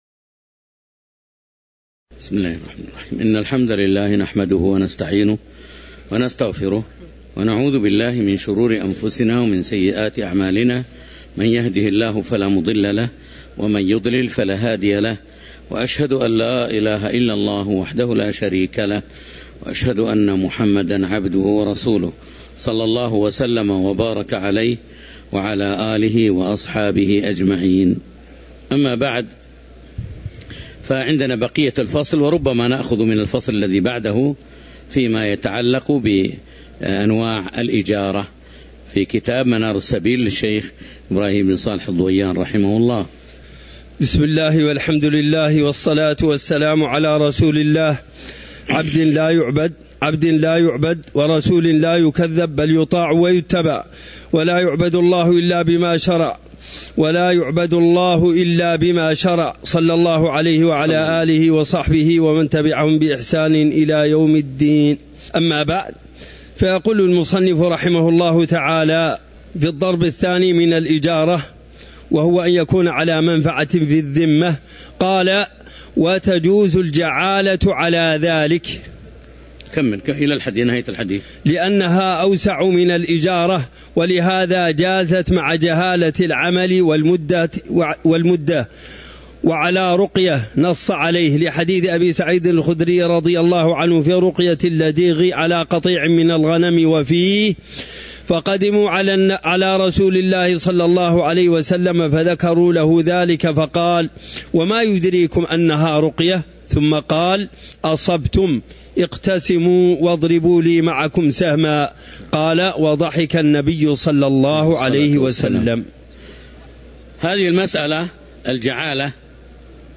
تاريخ النشر ٦ شعبان ١٤٤٣ هـ المكان: المسجد النبوي الشيخ